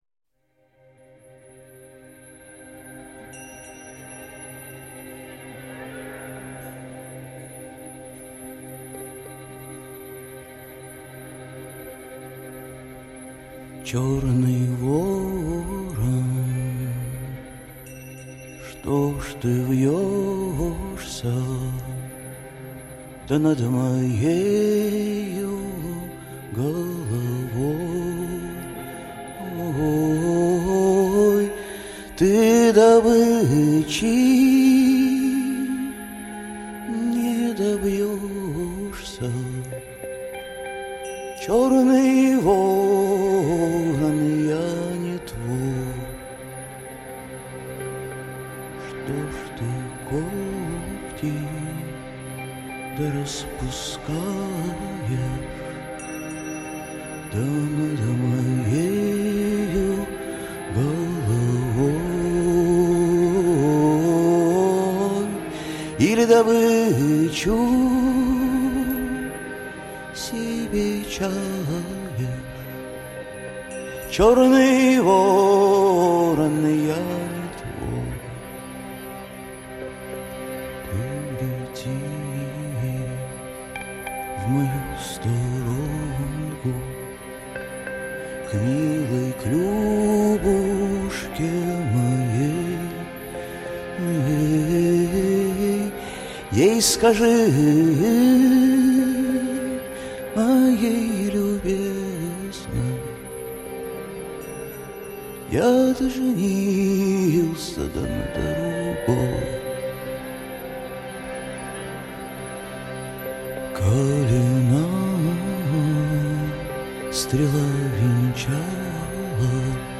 И песня очень проникновенная.